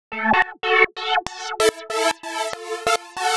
Index of /musicradar/uk-garage-samples/142bpm Lines n Loops/Synths
GA_SacherPad142A-01.wav